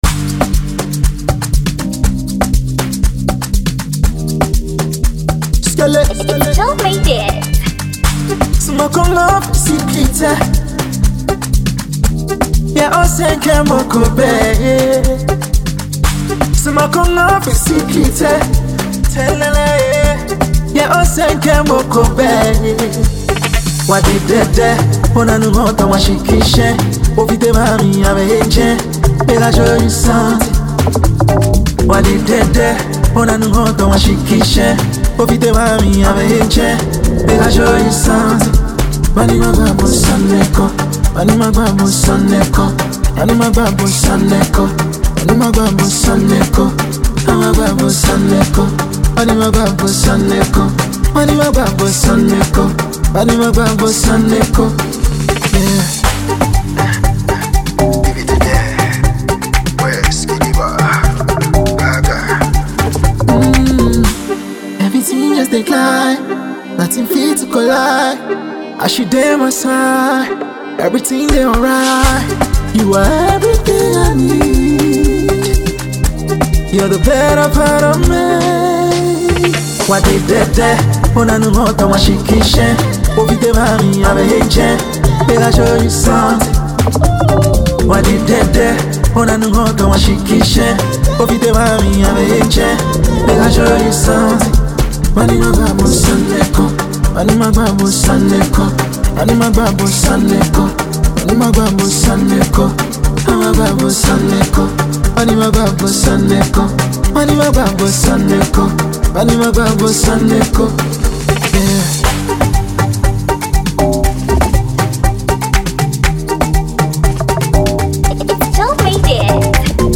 a Ghanaian singer
Enjoy this dope and well-mixed production.